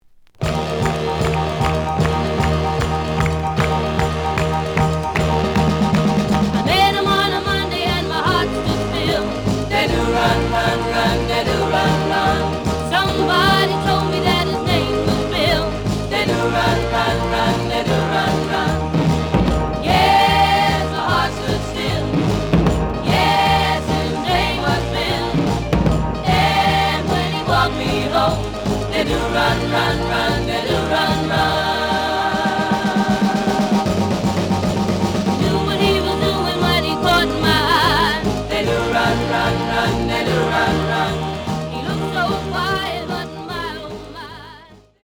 試聴は実際のレコードから録音しています。
The audio sample is recorded from the actual item.
●Genre: Rhythm And Blues / Rock 'n' Roll